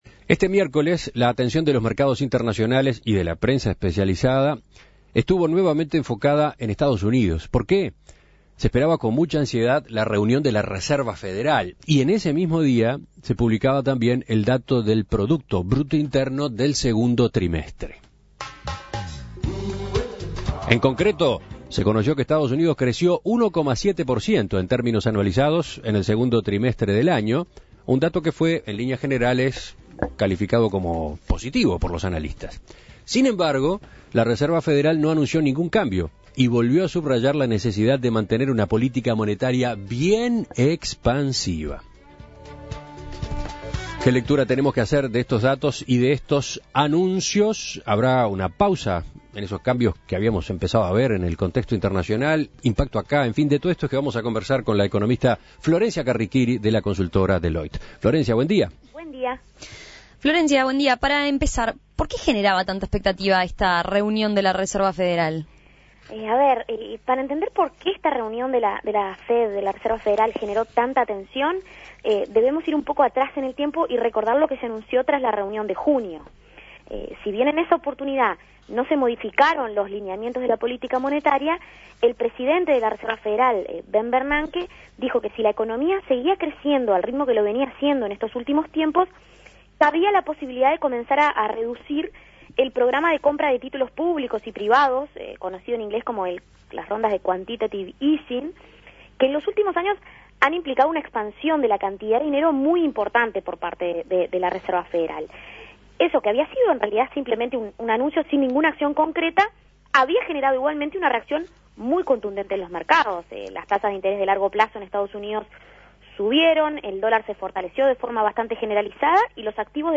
Análisis Económico El dato de PBI de Estados Unidos y las decisiones de la Reserva Federal: ¿cambios o continuidad en las tendencias económicas internacionales?